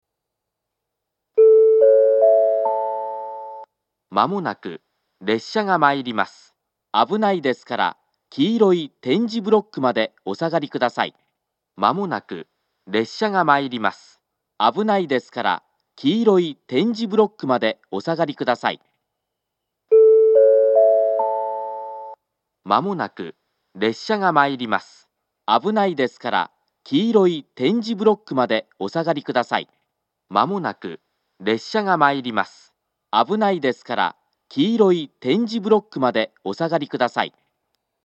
２番線上り接近放送